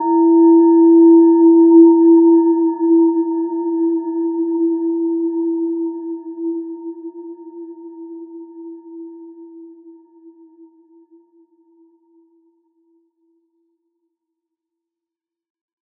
Gentle-Metallic-4-E4-mf.wav